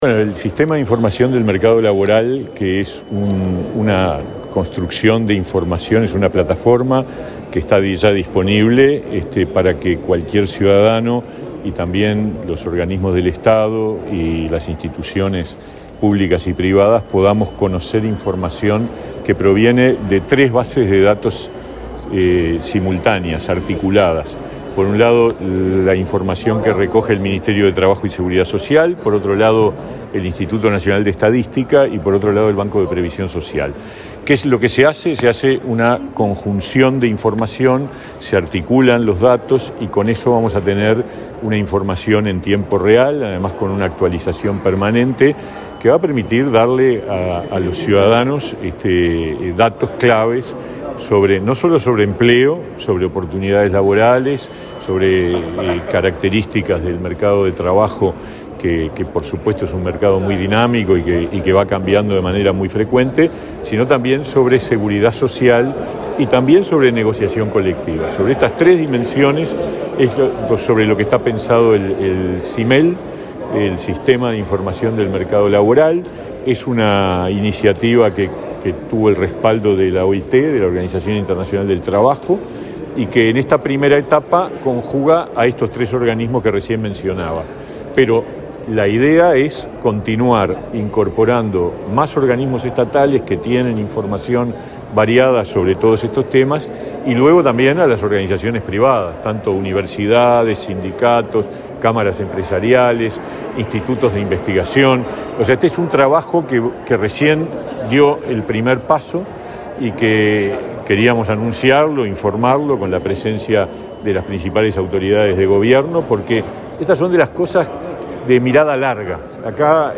Declaraciones del ministro de Trabajo, Pablo Mieres
Luego dialogó con la prensa.